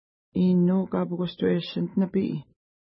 Pronunciation: i:nnu: ka:pəkəstweʃənt nəpi:
Pronunciation